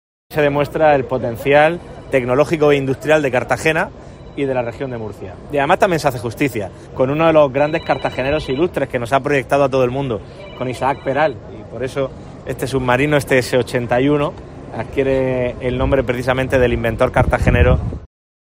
Fernando López Miras, presidente de la Región de Murcia
El presidente del Gobierno de la Región de Murcia, Fernando López Miras, participó hoy en Cartagena, junto a la ministra de Defensa, Margarita Robles, en el acto de entrega a la Armada del submarino S-81 Isaac Peral, que representa “la mejor prueba de la capacidad tecnológica e industrial de Cartagena y de la Región de Murcia”, subrayó.